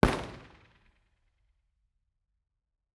IR_EigenmikeHHR1_processed.wav